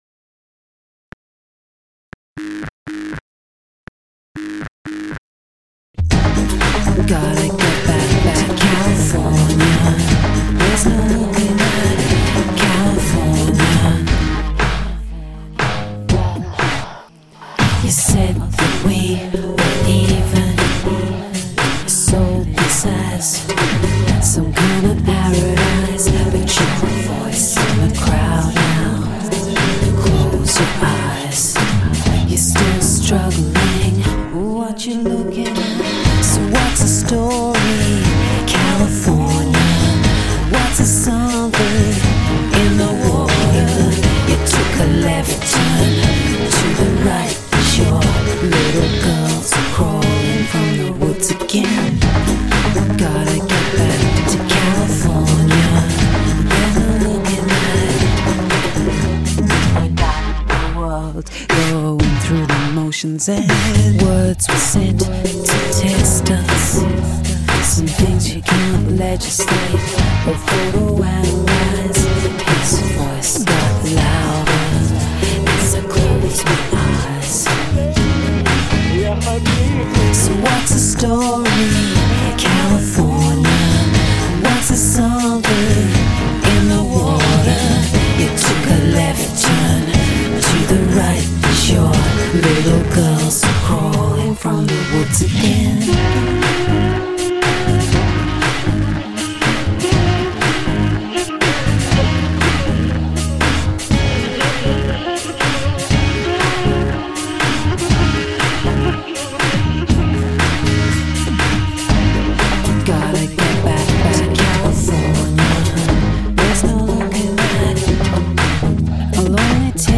Inventive electro with commercial sensibilities.